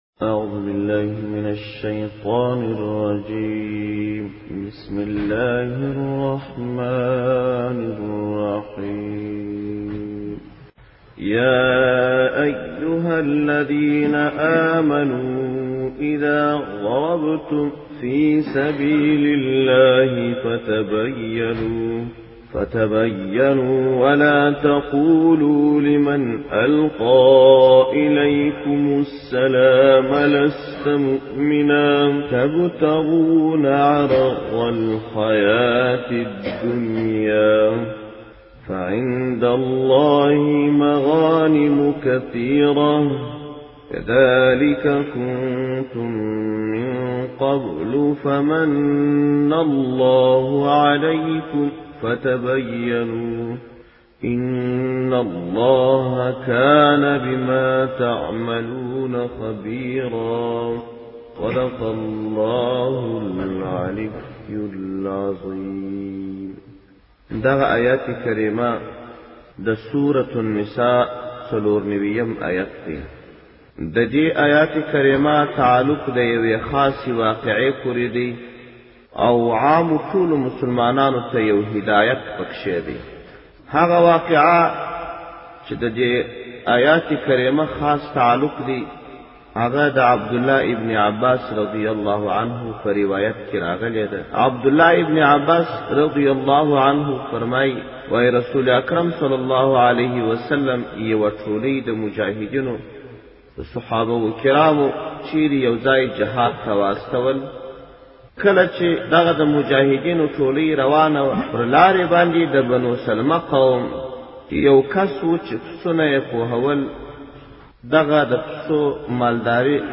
د قرآن کریم تفسیر او ترجمه